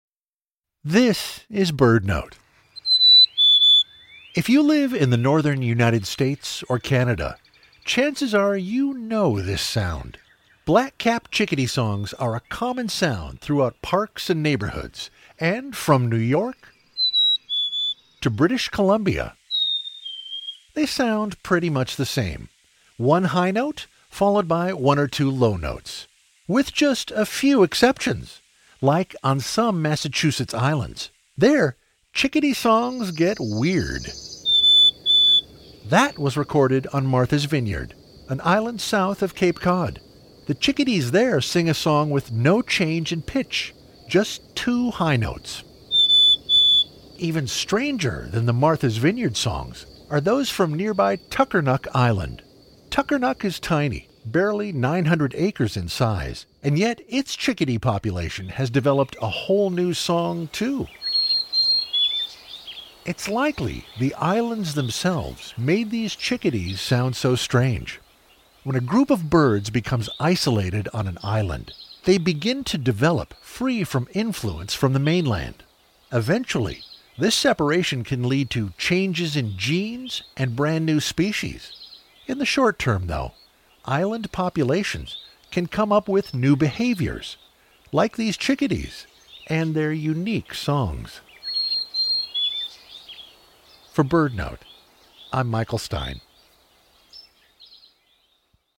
The song of the Black-capped Chickadee pretty much the same throughout the U.S. and Canada — with just a few exceptions, like on some Massachusetts islands. Chickadees on Martha’s Vineyard and tiny Tuckernuck Island nearby have developed songs entirely their own. It’s likely that the birds’ isolation from mainland birds led them to develop unique behaviors.